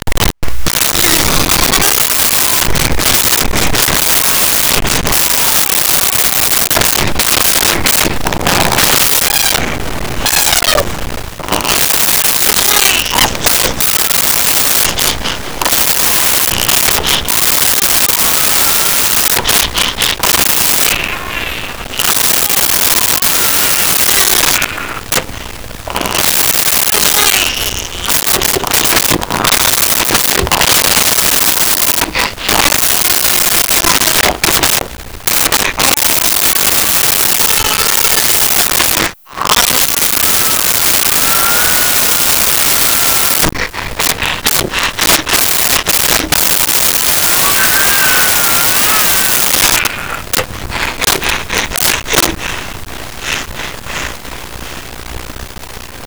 Crying Baby Light Short
Crying Baby Light Short.wav